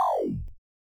electronFlare.ogg